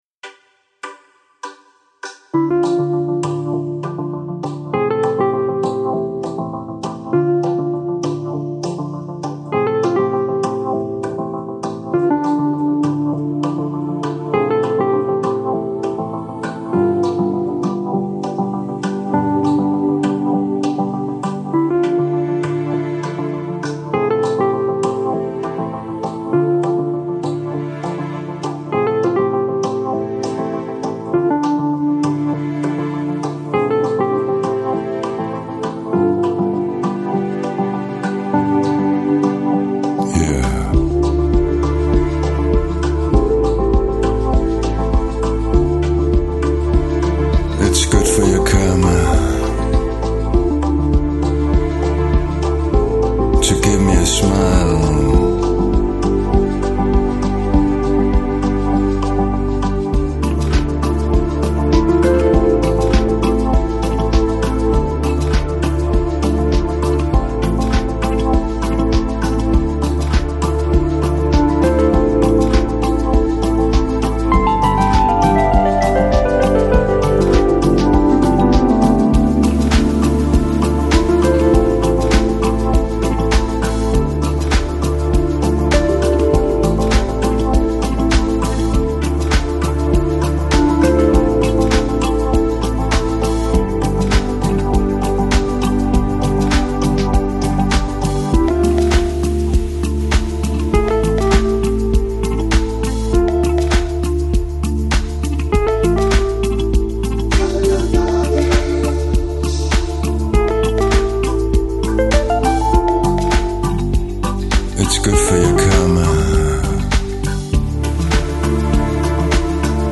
Жанр: Electronic, Chill out, Downtempo, Lounge